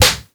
punch_slap_whack_hit_02.wav